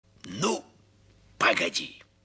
Уверенно: Ну, погоди! (звук с фразой волка)